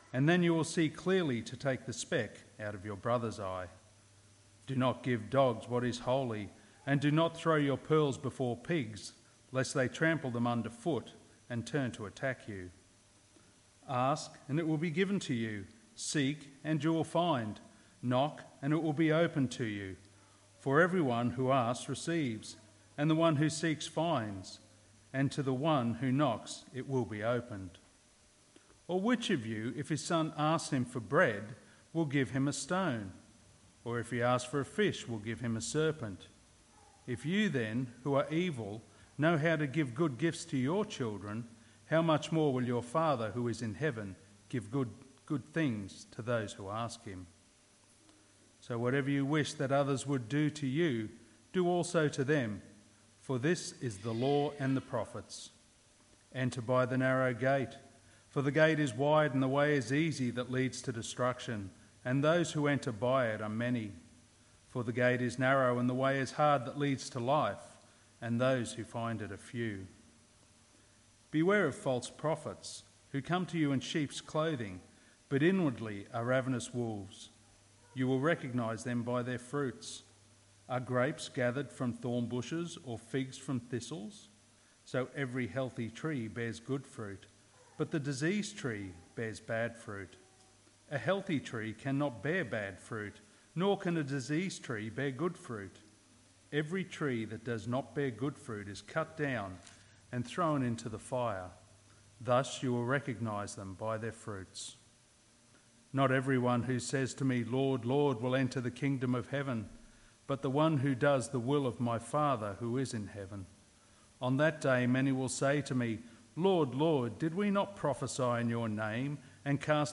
Ecclesiastes | Sermon Books | Christian Reformation Community Church